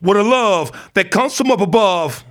RAPHRASE11.wav